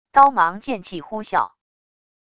刀芒剑气呼啸wav音频